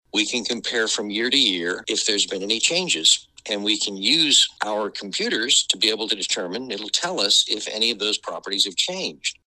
CLICK HERE to listen to commentary from Oklahoma County Assessor Larry Stein.